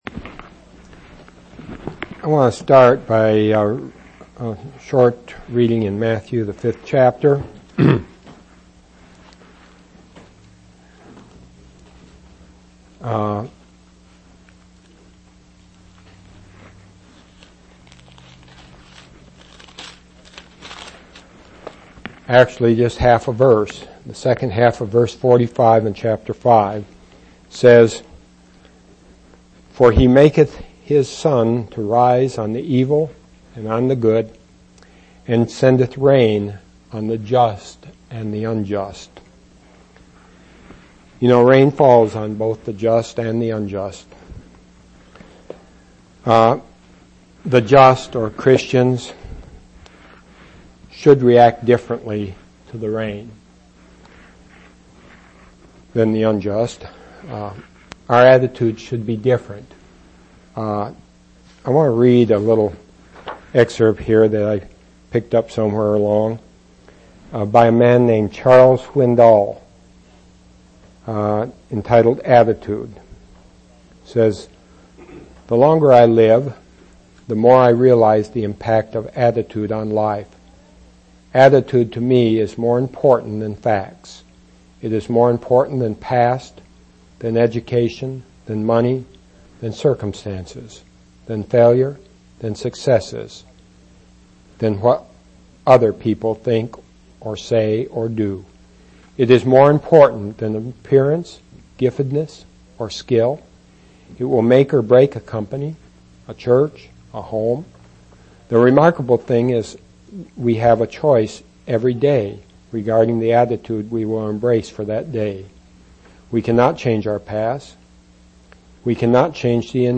12/12/1999 Location: East Independence Local Event